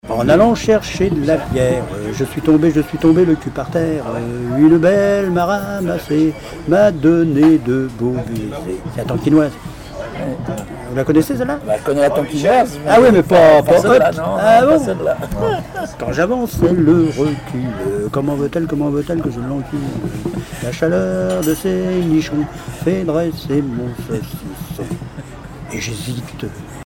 circonstance : bachique
Pièce musicale inédite